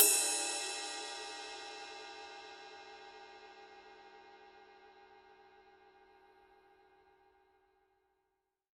20" Crash Ride Becken
RUDE continues to be the leading choice of sound for raw, merciless and powerful musical energy in Rock, Metal, and Punk.
20_ride-crash_body.mp3